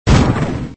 pengzhuang.mp3